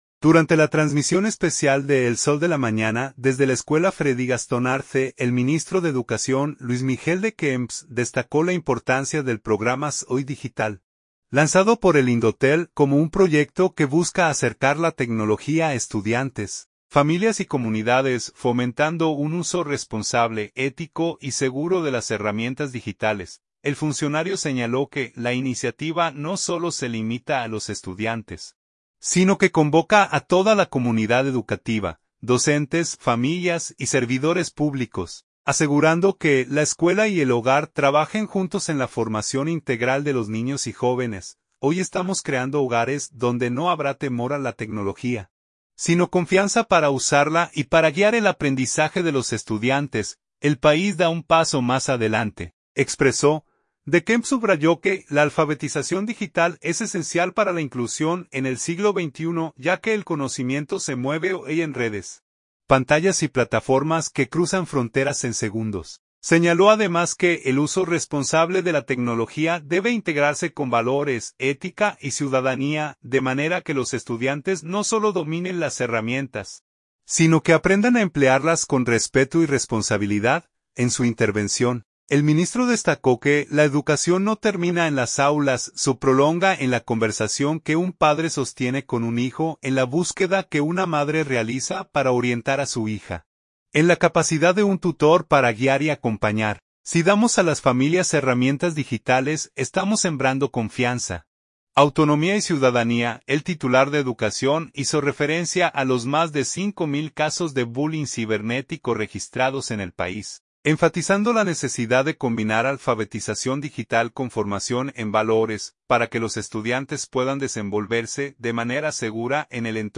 Durante la transmisión especial de El Sol de la Mañana desde la Escuela Freddy Gastón Arce, el ministro de Educación, Luis Miguel De Camps, destacó la importancia del programa Soy Digital, lanzado por el Indotel, como un proyecto que busca acercar la tecnología a estudiantes, familias y comunidades, fomentando un uso responsable, ético y seguro de las herramientas digitales.